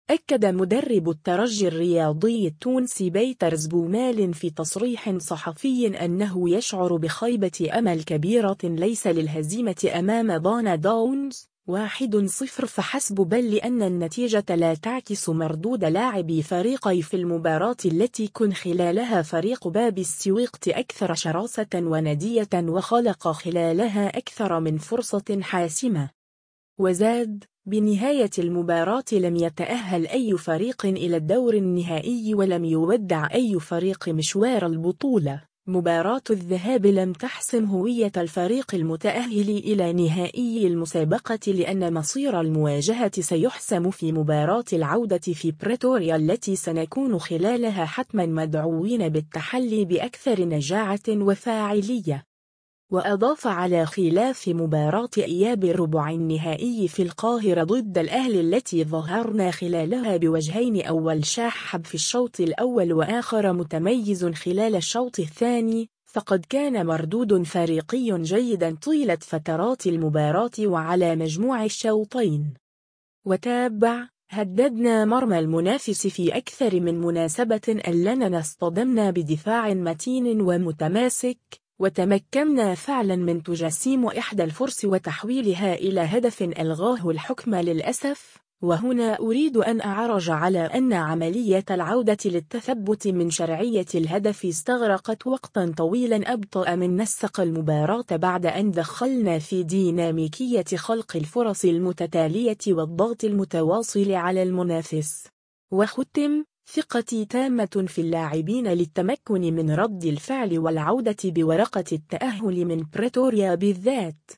أكّد مدرّب الترجي الرياضي التونسي باتريس بومال في تصريح صحفي أنّه يشعر بخيبة أمل كبيرة ليس للهزيمة أمام ضان داونز (1-0) فحسب بل لأن النتيجة لا تعكس مردود لاعبي فريقي في المباراة التي كن خلالها فريق باب السويقة أكثر شراسة و نديّة و خلق خلالها اكثر من فرصة حاسمة.